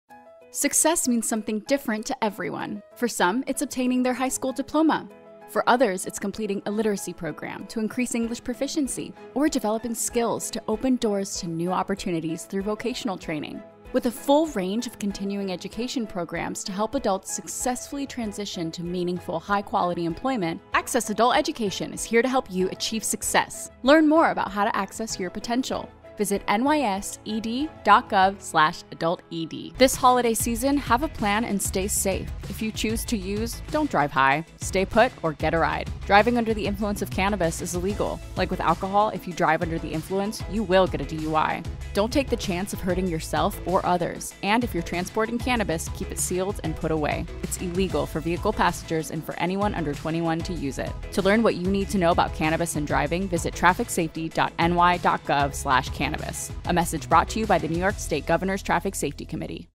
Female Voice Over, Dan Wachs Talent Agency.
Vivacious, Enthusiastic, Bilingual
Public Service